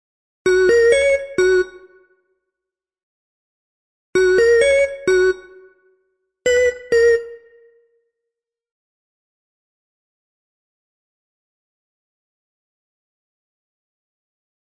他们的女人" 俗气的器官
Tag: 寒意 旅行 电子 舞蹈 looppack 样品 毛刺 节奏 节拍 低音 实验 器乐